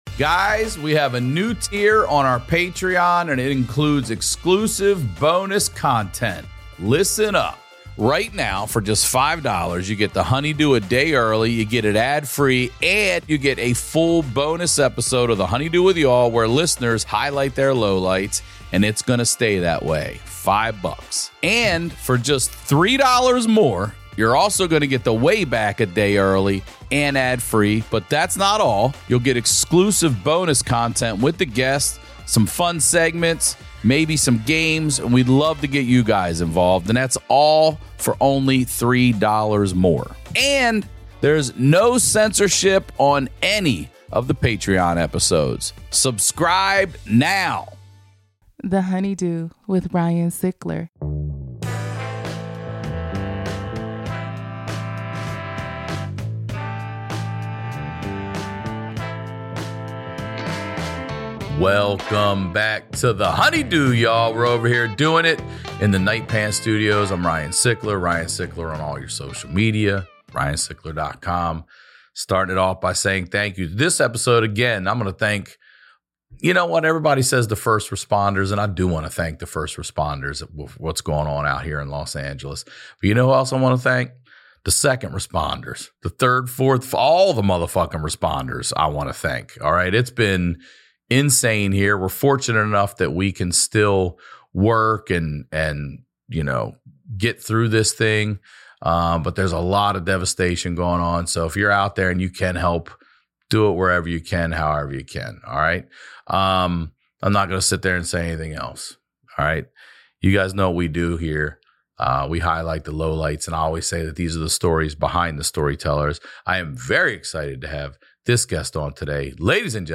My HoneyDew this week is actor and comedian Juston McKinney!